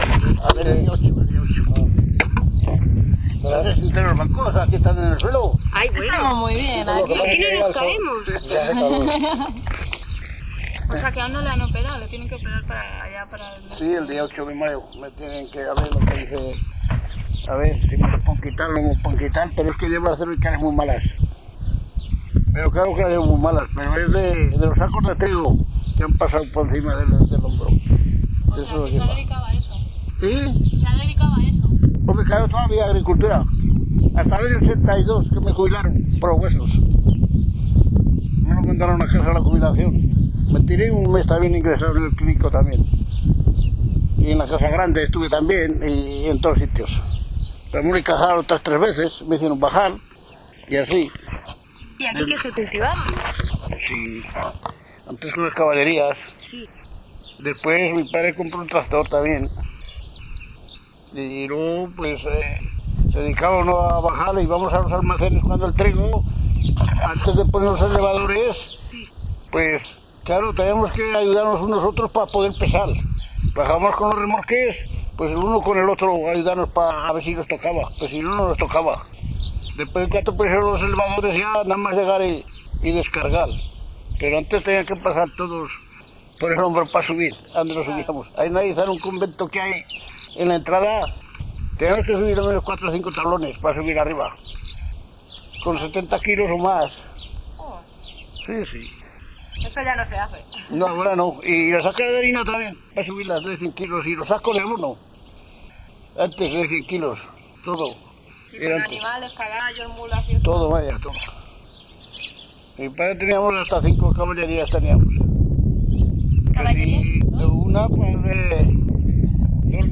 Locality Embid de Ariza